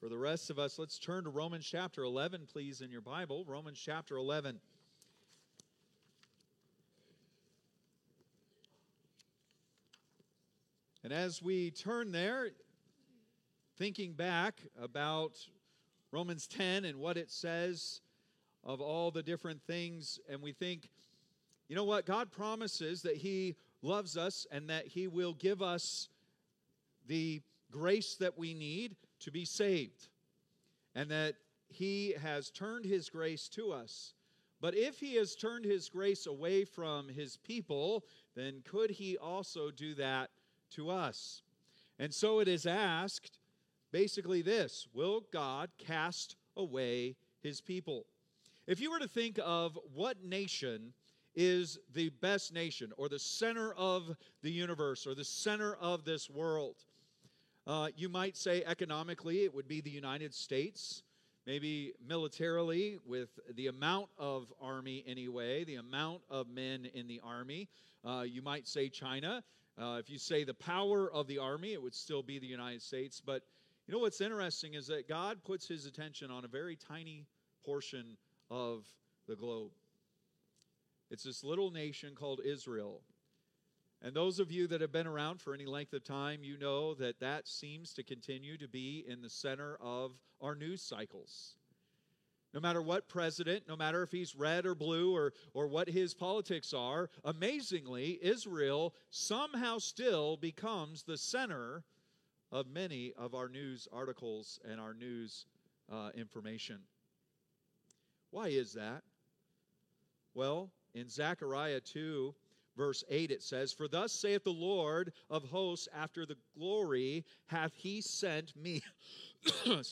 Date: March 9, 2025 (Sunday Morning)